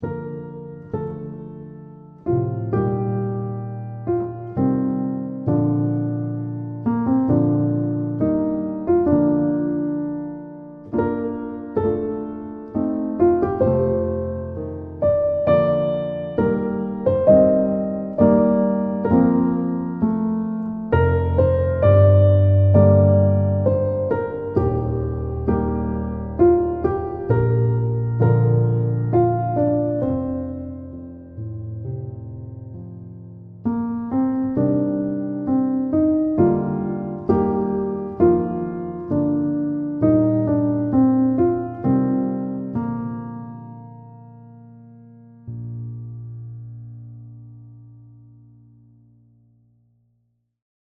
Reflection At The Water's Edge (Contemplative/Reverent)